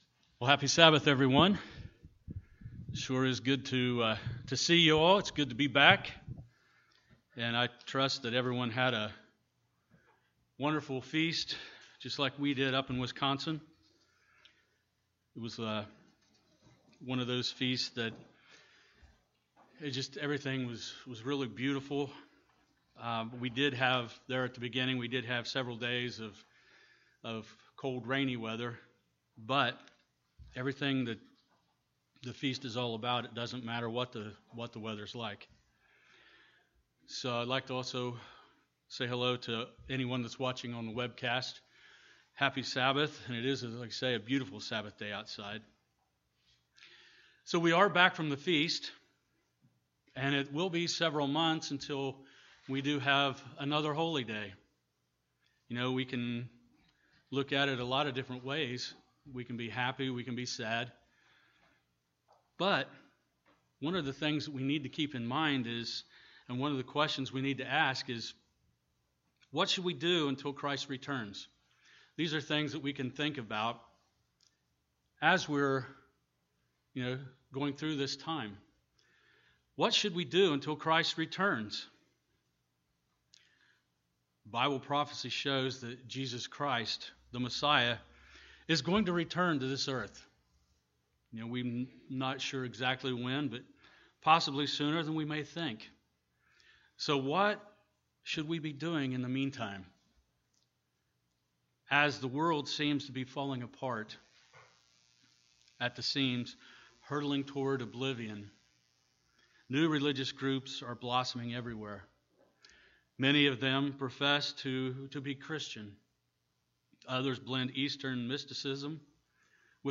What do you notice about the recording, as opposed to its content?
Given in Portsmouth, OH Paintsville, KY